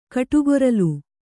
♪ kaṭugoralu